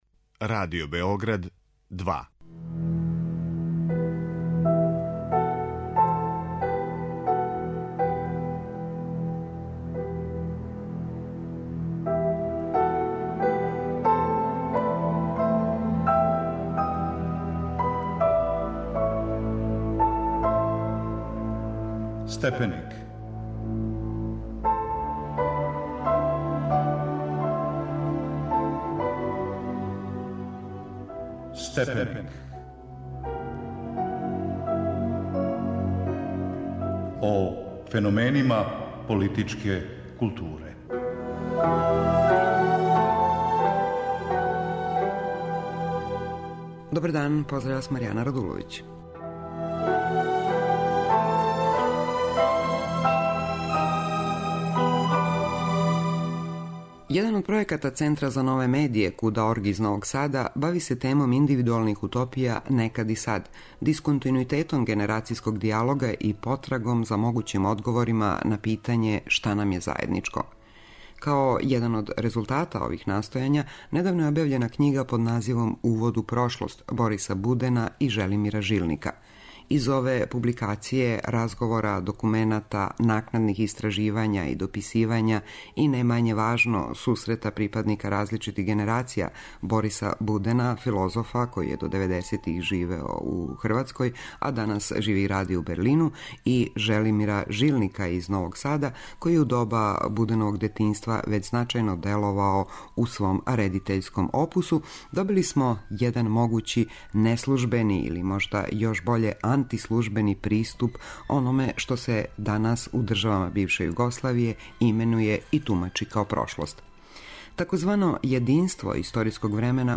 У Омладинском центру ЦК 13 у Новом Саду разговарало се о књизи "Увод у прошлост", својеврсном контекстуализованом разговору Бориса Будена и Желимира Жилника.
У емисији Степеник говоре: Борис Буден, филозоф из Берлина и Желимир Жилник, филмски редитељ из Новог Сада.